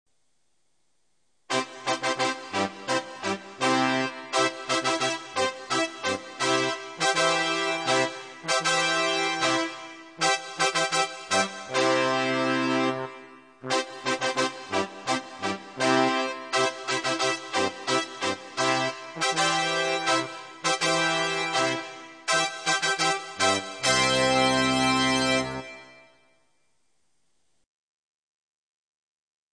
Fanfary
fanfary.mp3